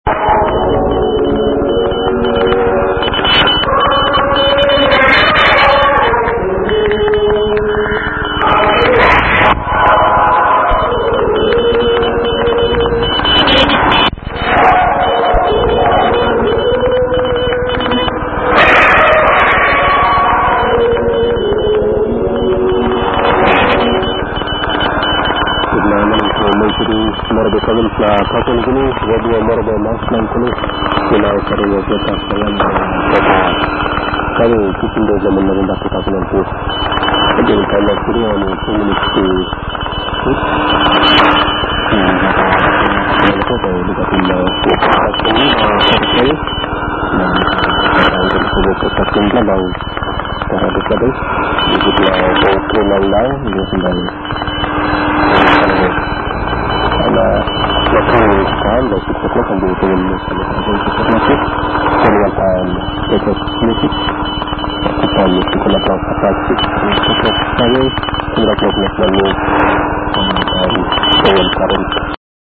As usual Beverage antennas were installed into all relevant DX directions up to 300m.
Already the first day of the Camp enabled a special DX experience on 3220 kHz at around 1950 UTC. Radio Morobe, a local station from Papua New Guinea, was audible on the Asia Beverage with sensational signal strength (S=9).
Receiver: NRD-525
Location: 45 km northeast of Nuremberg, Germany